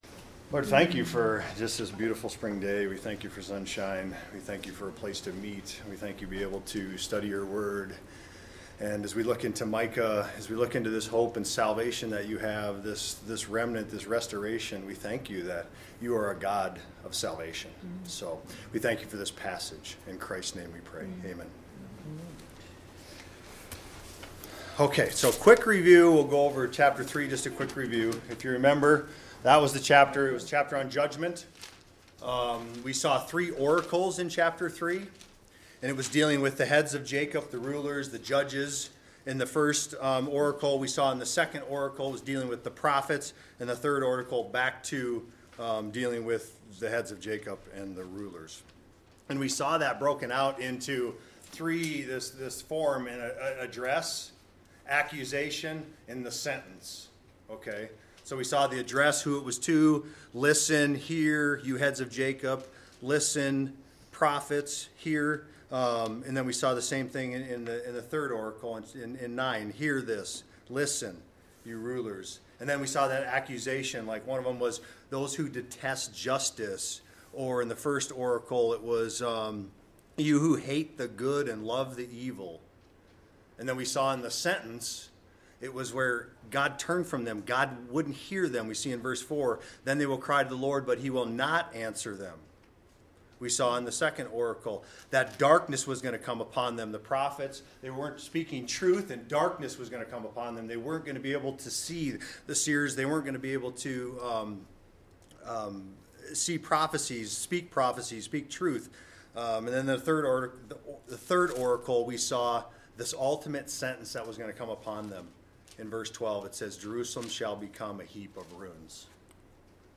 Expositional sermons and Sunday school lessons from Sojourn Church in Spearfish, SD.